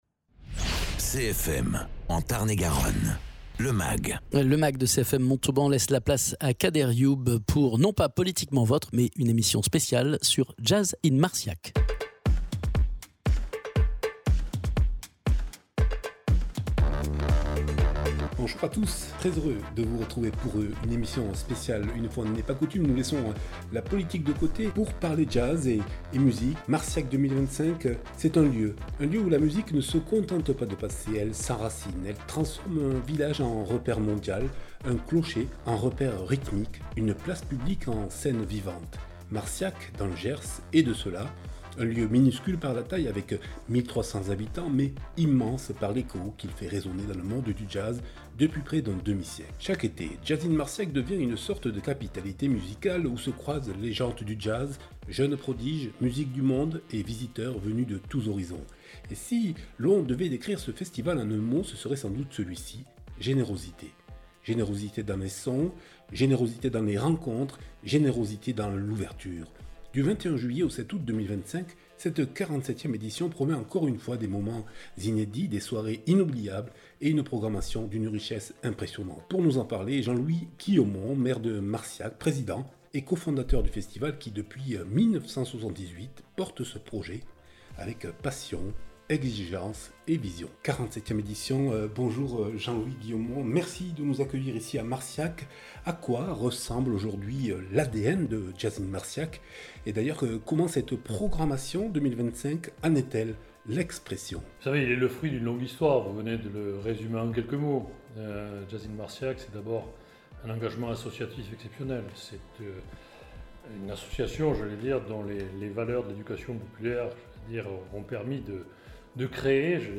Invité(s) : Jean-Louis GUILHAUMON maire de Marciac et programmateur du festival Jazz in Marciac